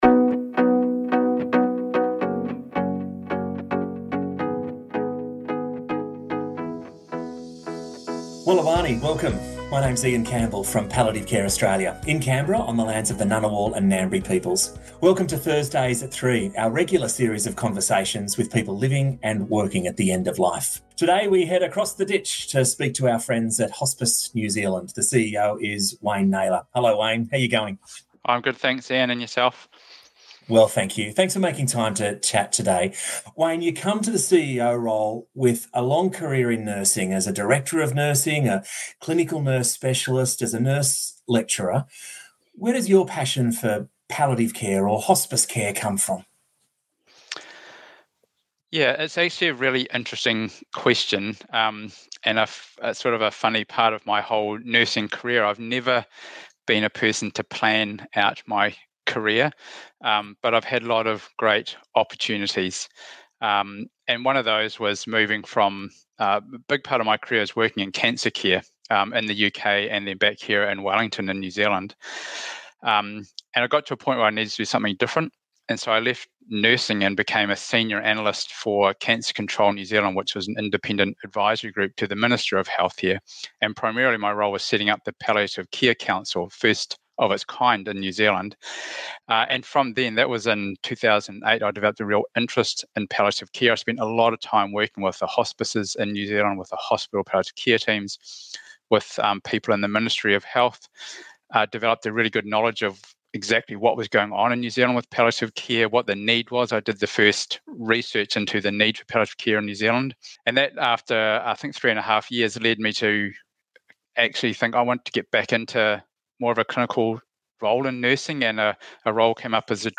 Welcome to Thursdays@3 our regular series of conversations with people living and working at the end of life.